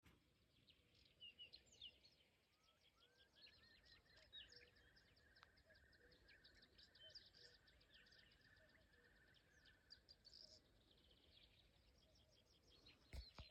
Birds -> Waders ->
Whimbrel, Numenius phaeopus
StatusSinging male in breeding season